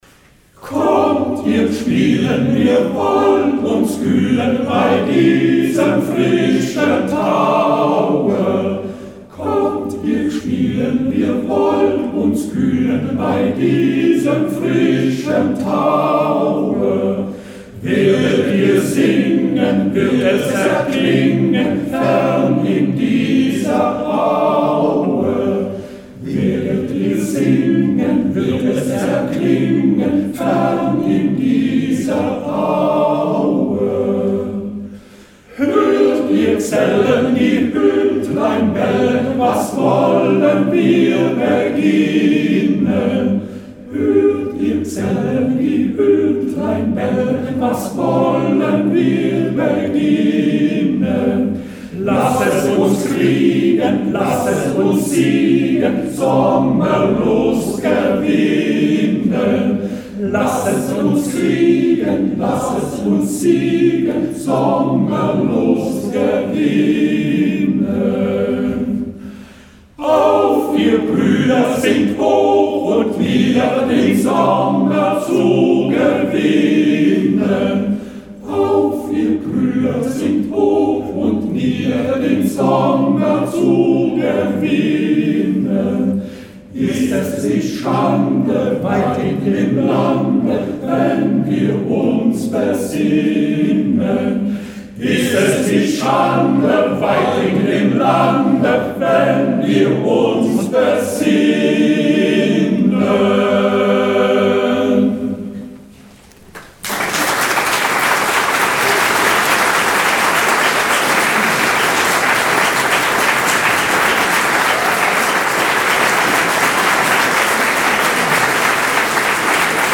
Hörbeispiele von unserer CD vom 27. Juni 2007: 70. Hausmusik.